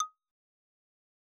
Raise-Your-Wand / Sound / Effects / UI / Modern11.wav